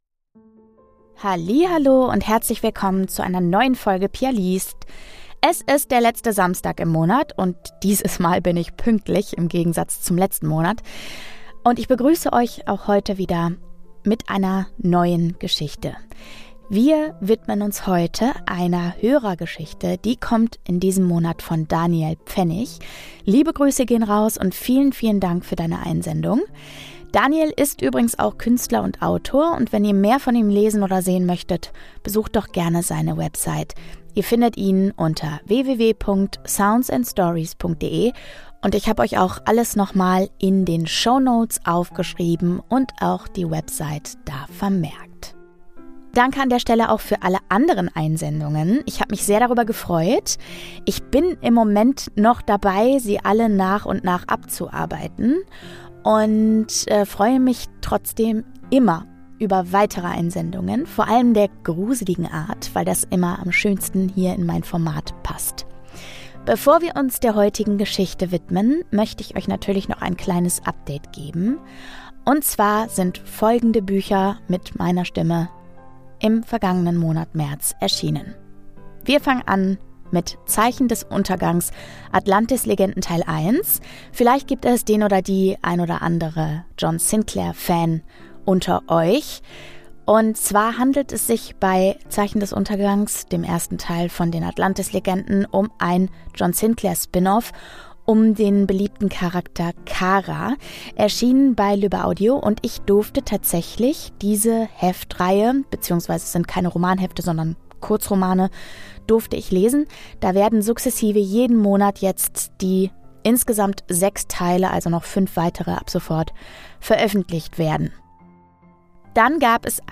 :) Kleine Warnung: Hier werden zumeist gruselige Geschichten gelesen; sie können explizite Gewaltdarstellung und Horrorelemente enthalten.
Jeden letzten Samstag im Monat gibt es dabei eine meist gruselige Geschichte, die ich inzwischen mit Geräuschen, Musik und Ambient-Sounds versehe, um euch ein optimales Hör- und damit Gruselerlebnis zu geben. Dabei lese ich sowohl Creepypastas, als auch Geschichten aus dieser Community.